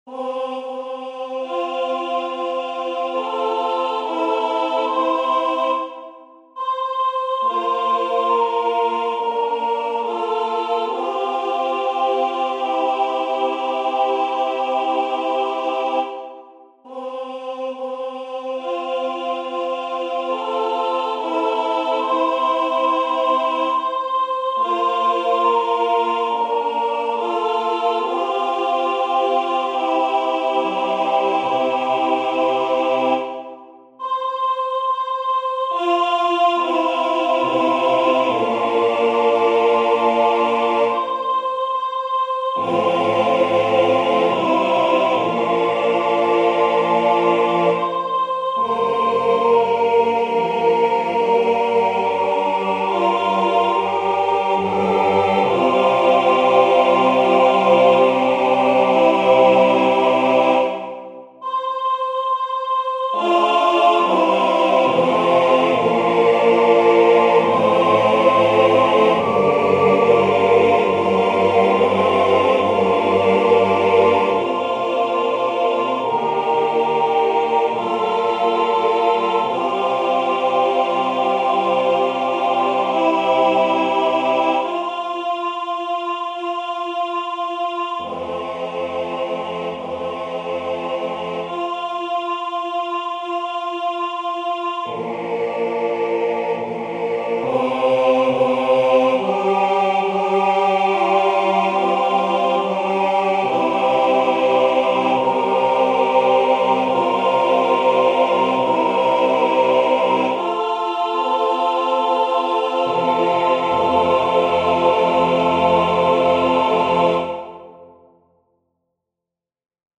Voicing/Instrumentation: SATB
A Cappella/Optional A Capella